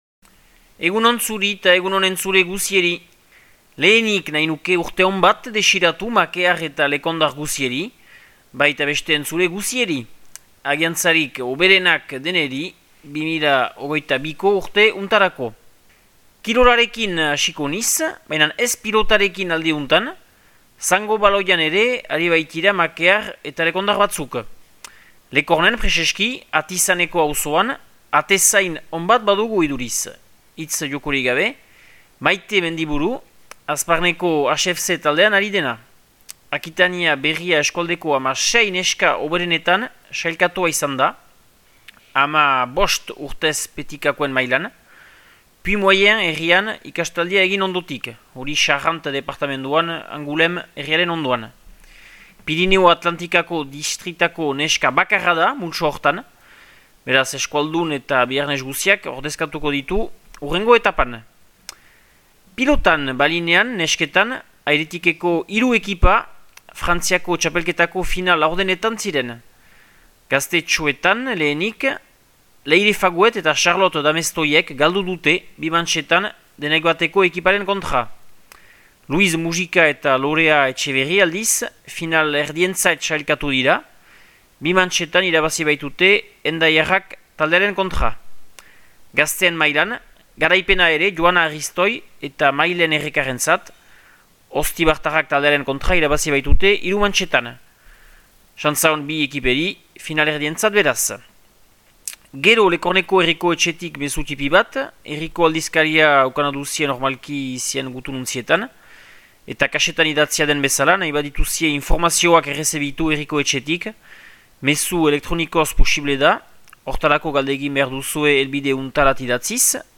Urtarrilaren 5eko Makea eta Lekorneko berriak